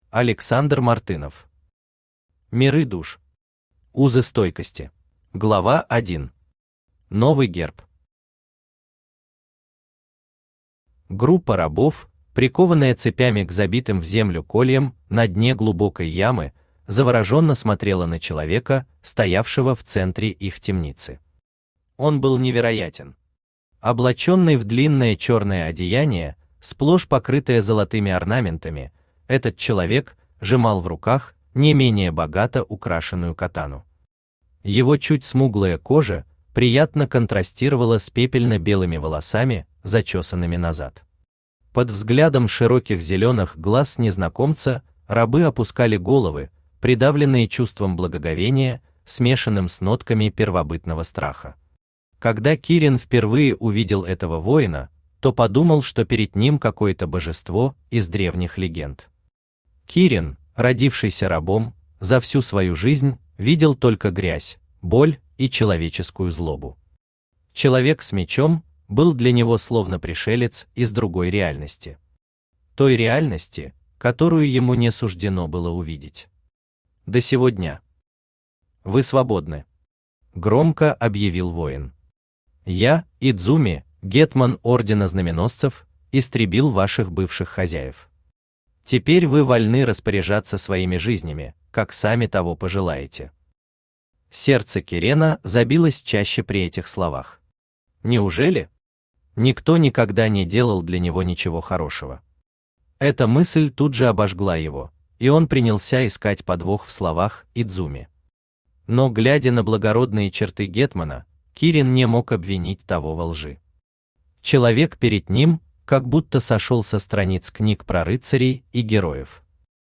Aудиокнига Миры Душ. Узы стойкости Автор Александр Мартынов Читает аудиокнигу Авточтец ЛитРес.